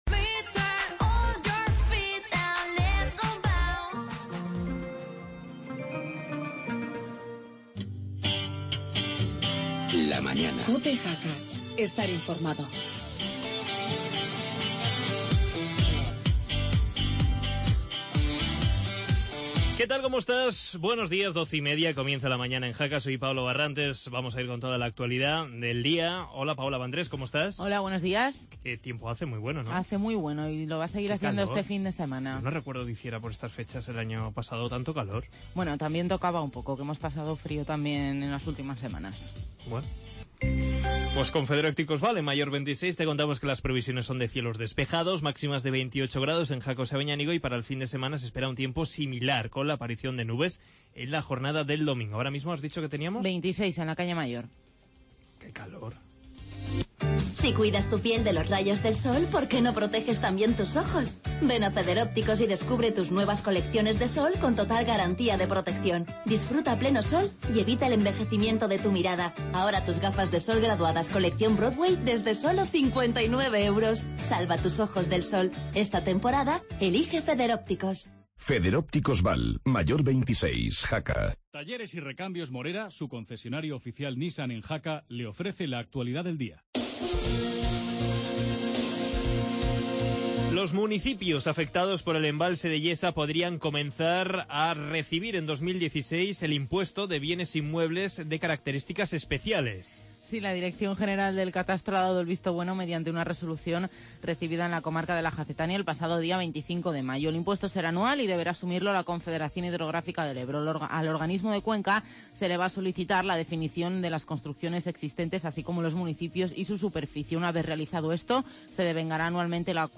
AUDIO: Actualidad de las comarcas y entrevista a la asociación Doña Sancha.